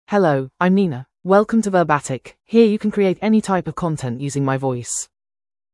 FemaleEnglish (United Kingdom)
NinaFemale English AI voice
Nina is a female AI voice for English (United Kingdom).
Voice sample
Nina delivers clear pronunciation with authentic United Kingdom English intonation, making your content sound professionally produced.